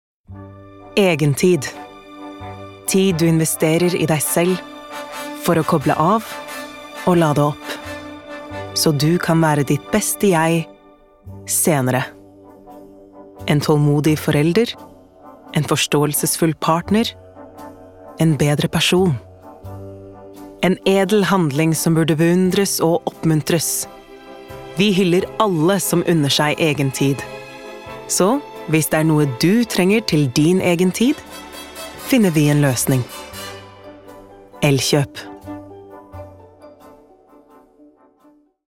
• Female
Elkjop. Norweigan, Clear, Smooth